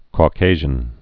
(kô-kāzhən, -kăzhən)